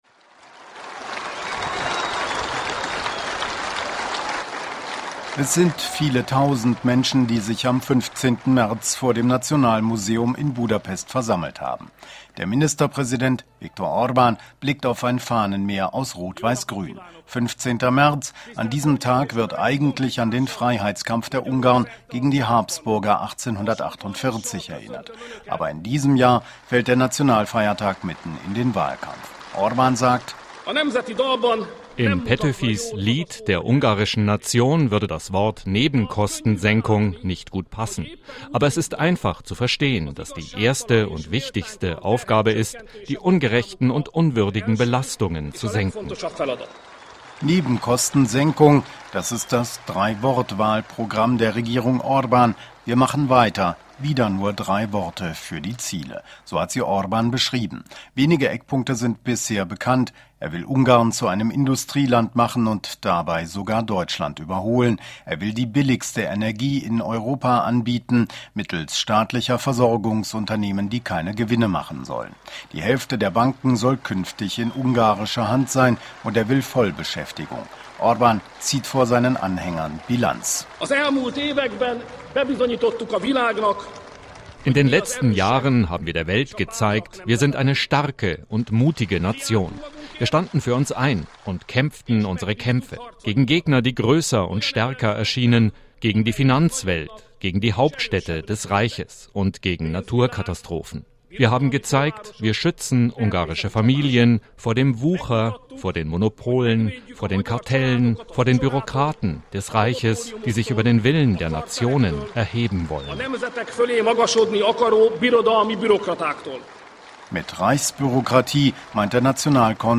Hörfunk-Beitrag